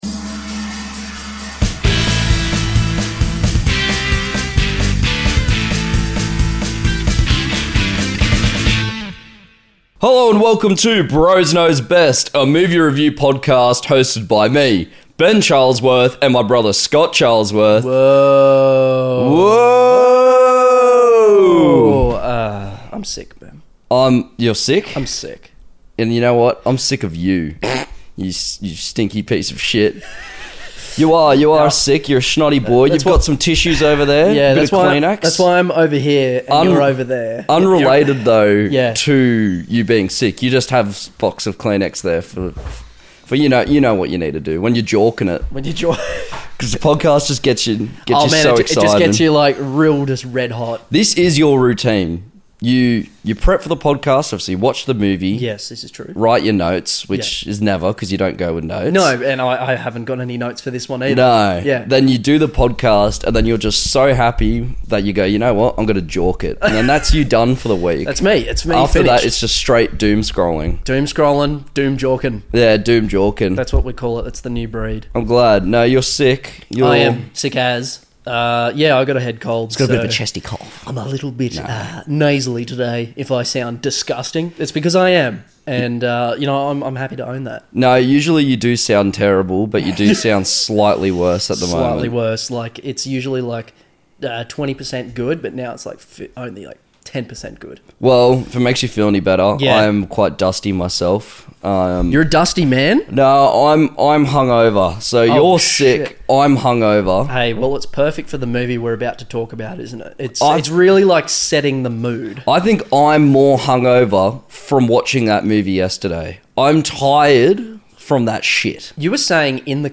This episode includes two angry boys, outbursts into song and society.